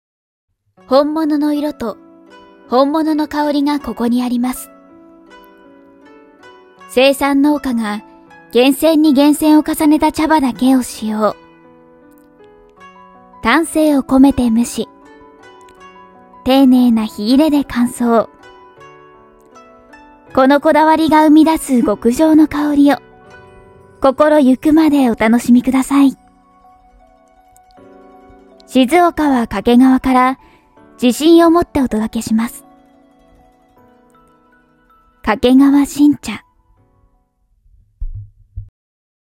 丁寧・高品質・リーズナブルなプロの女性ナレーターによるナレーション収録
商品PR